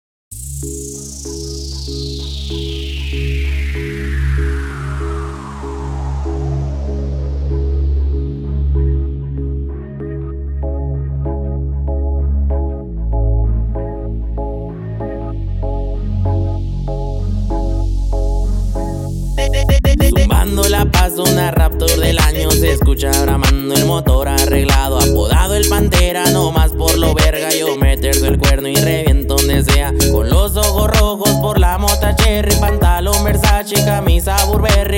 Жанр: Латино